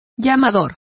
Complete with pronunciation of the translation of caller.